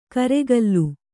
♪ karegallu